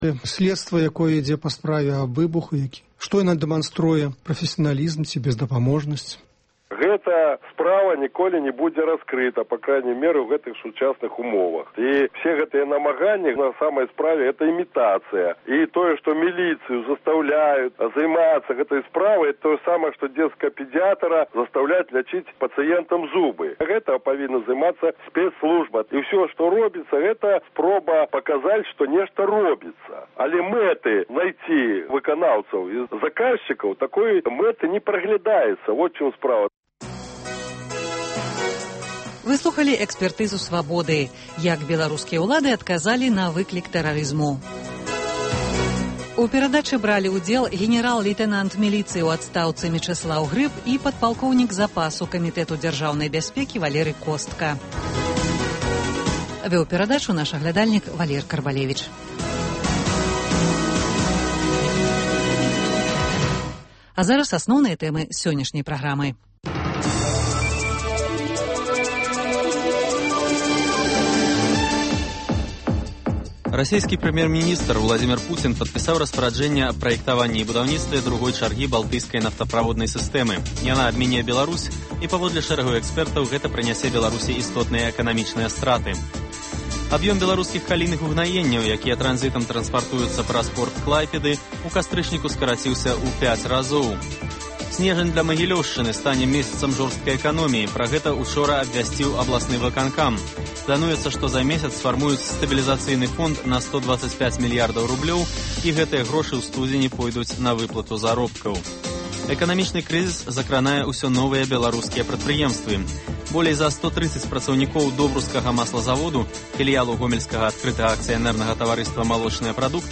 Гутаркі без цэнзуры зь дзеячамі культуры й навукі